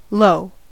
low: Wikimedia Commons US English Pronunciations
En-us-low.WAV